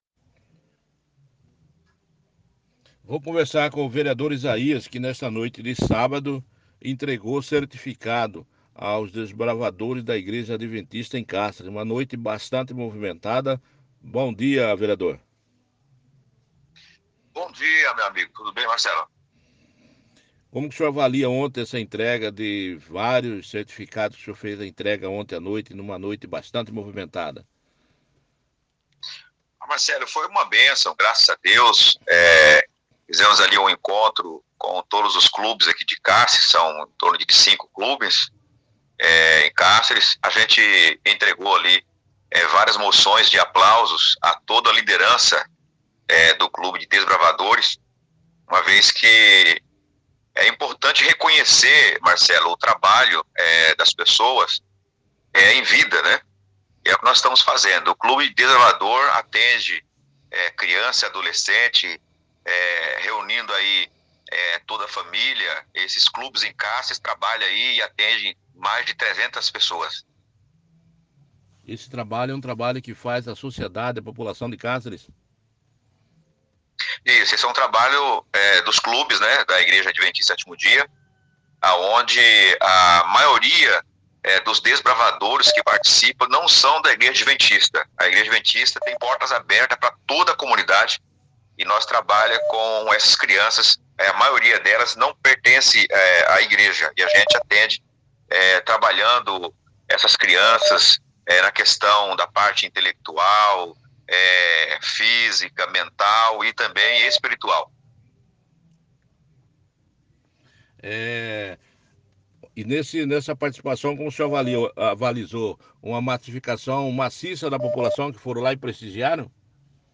O Parlamentar concedeu uma entrevista ao site Cáceres News.